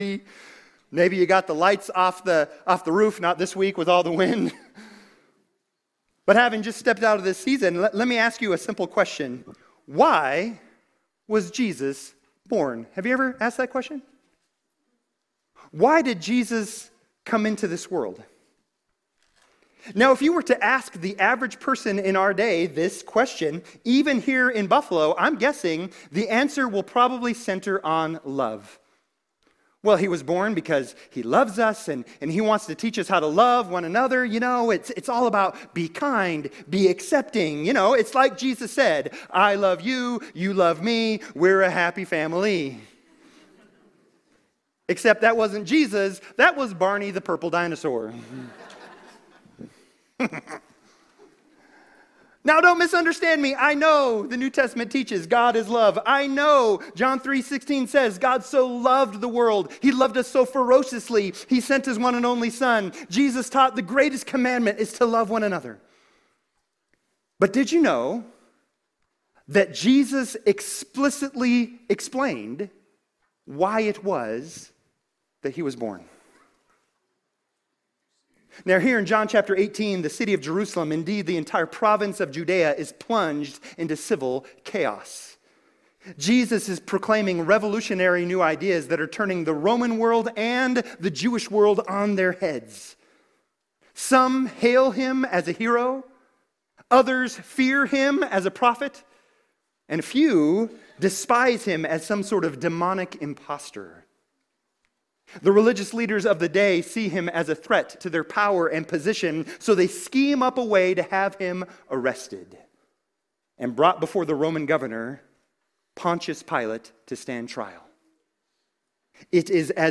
* partial recording due to technical difficulties